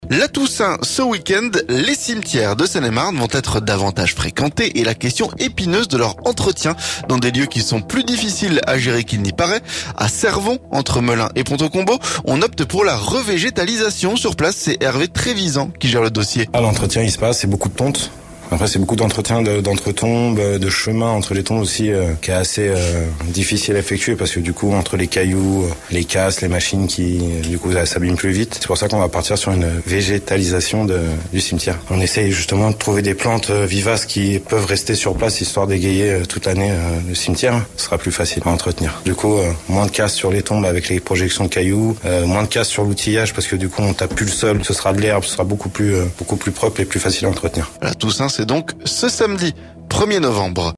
TOUSSAINT - L'entretien des cimetières de Seine-et-Marne, notre reportage